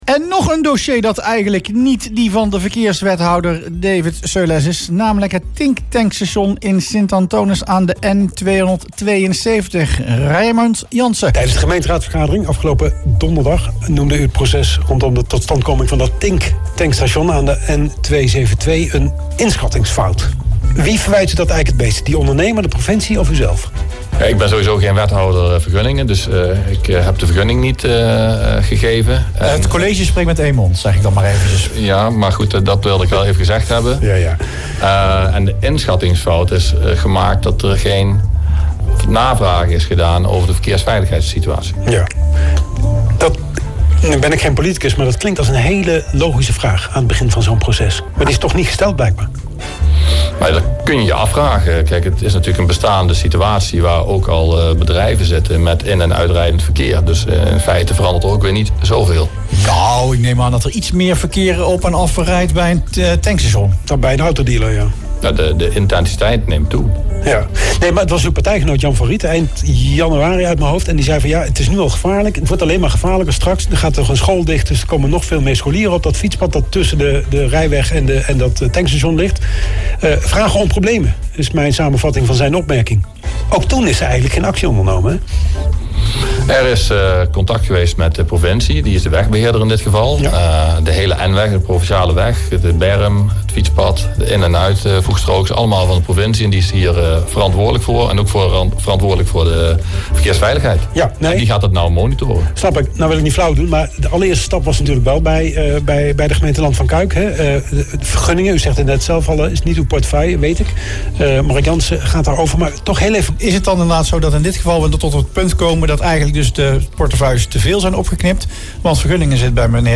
Verkeerswethouder Sölez over uitblijven verkeersonderzoek in Regelkevers